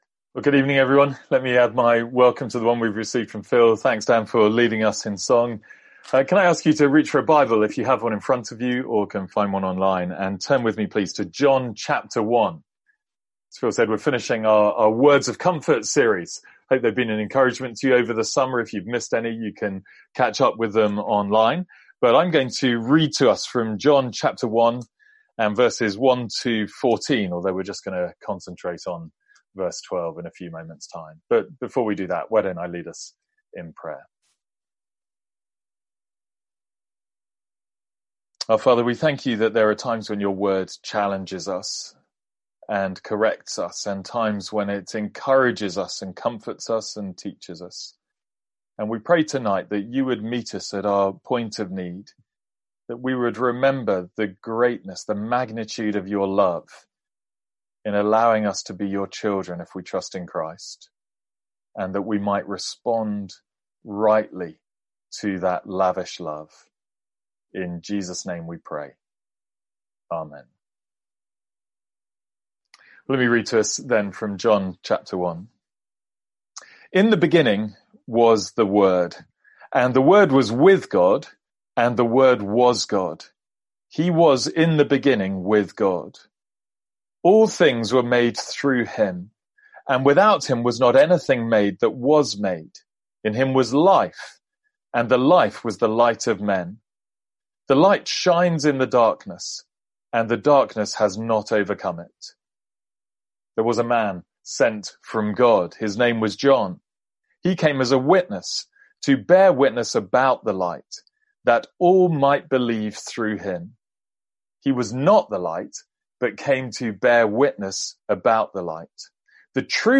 Sermons | St Andrews Free Church
From our evening series on 'Words of Comfort'.